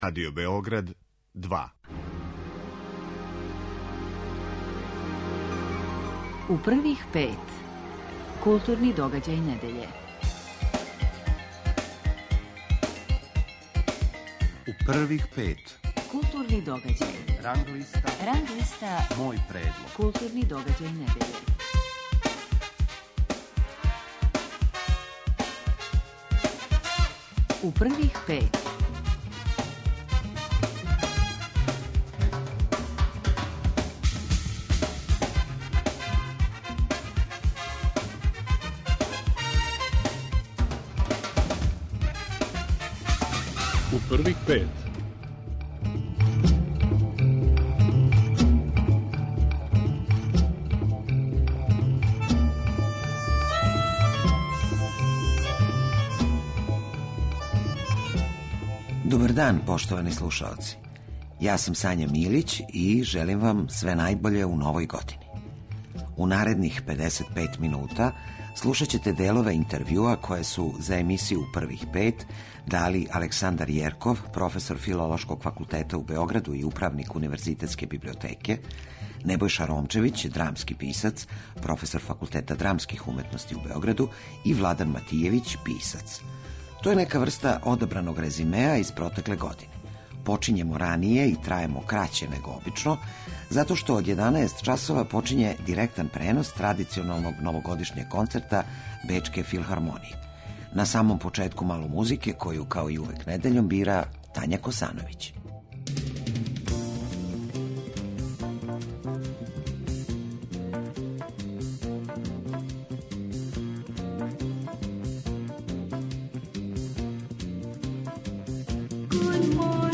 Специјално издање